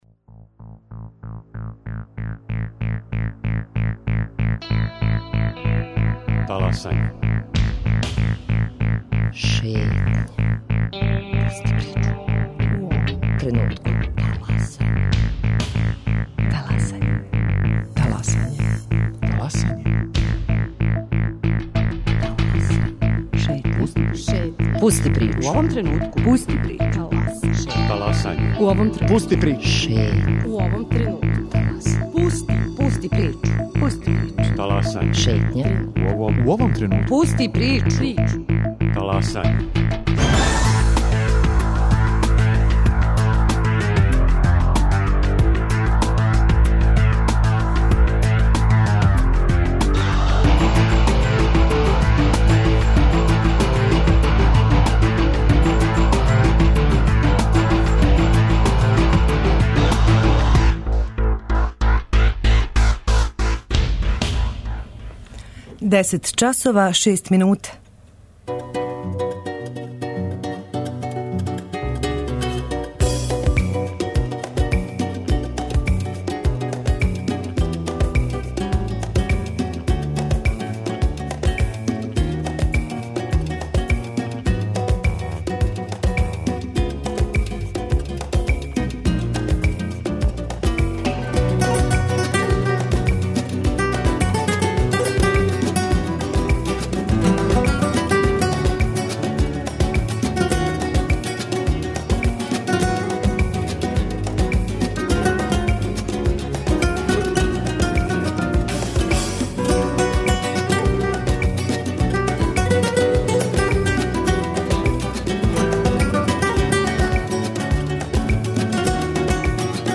Интервју у целости: У четвртак се на Брду код Крања одржава самит земља југоисточне Европе коме ће присуствовати и председник Француске Франсоа Оланд.